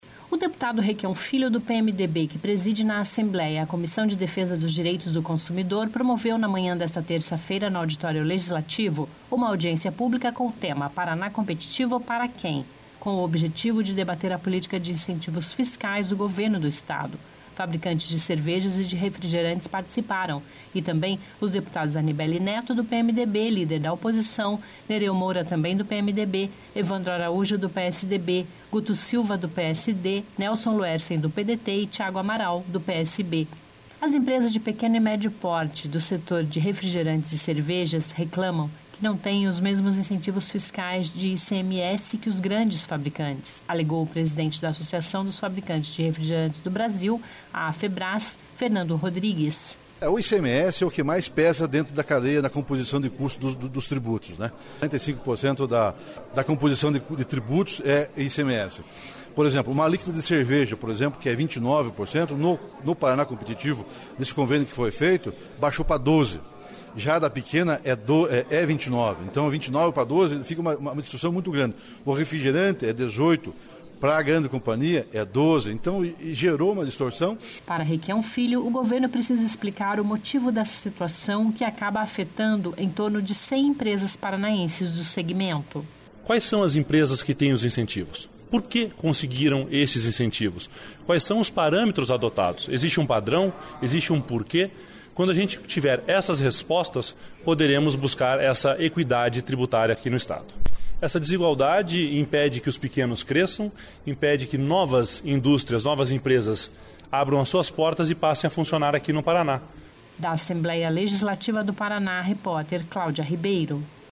Audiência pública debate política fiscal do Governo do Estado